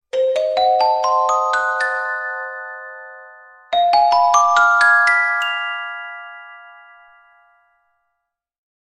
Spell-sound-effect.mp3